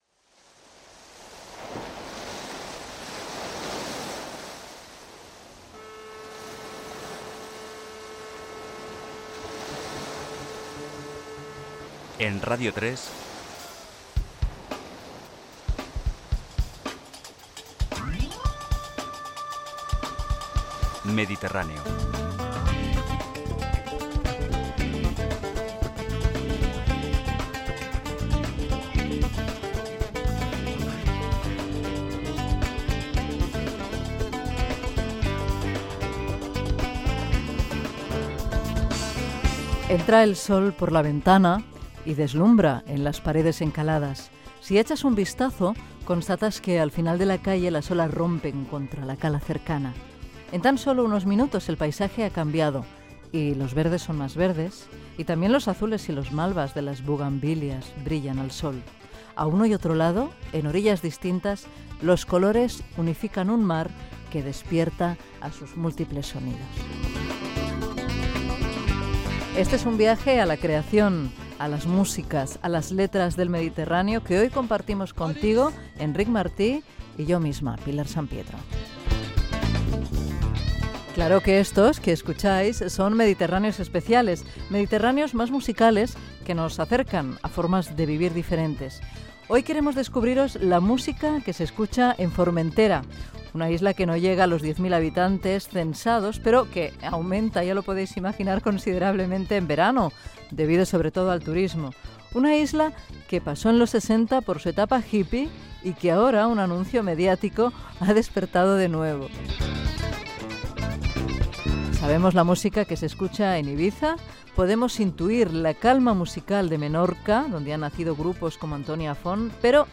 Careta del programa, el sol i els colors, equip, presentació de l'espai dedicat a la música que s'escolta a l'illa de Formentera, tema musical, comentari del grup que s'ha escoltat i lectura d'un fragment del llibre "La chica que soñaba con una cerilla y un bidón de gasolina", tema musical
FM